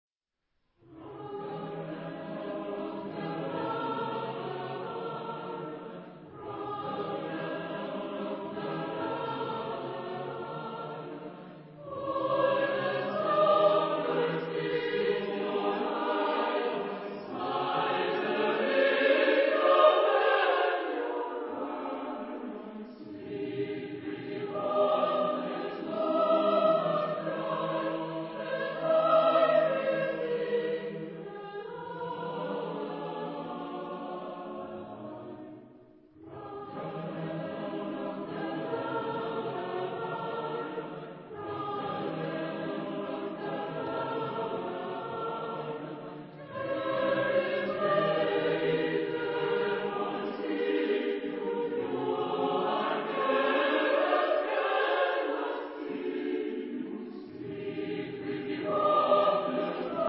Type of Choir: SATB  (4 mixed voices )
Soloist(s): Soprane (2)  (2 soloist(s))
Tonality: free tonality